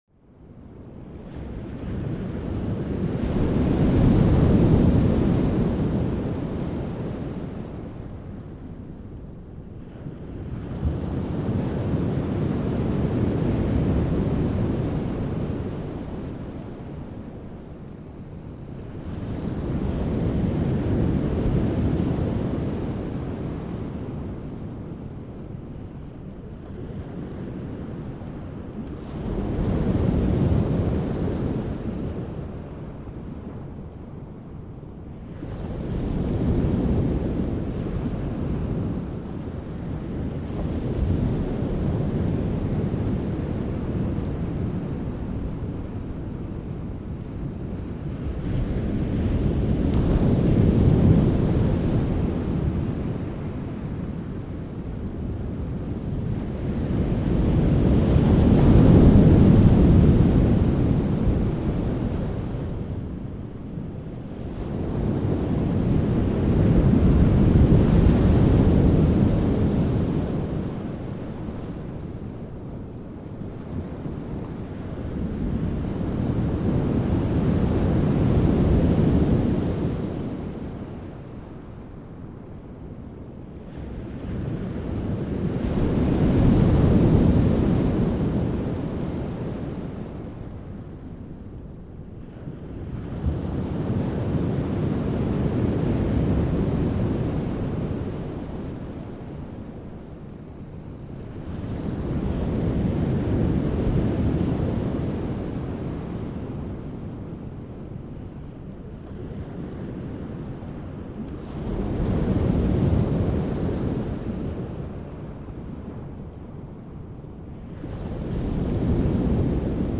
دانلود آهنگ وال 16 از افکت صوتی انسان و موجودات زنده
دانلود صدای وال 16 از ساعد نیوز با لینک مستقیم و کیفیت بالا
جلوه های صوتی